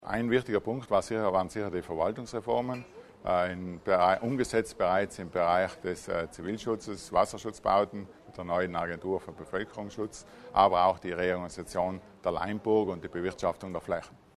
Landesrat Arnold Schuler zu den Reformschwerpunkten
Über seine Arbeit und Akzente im Hinblick auf Reformen, Nachhaltigkeit und Forschung hat Landesrat Schuler heute (20. Juni) in Trauttmansdorff informiert.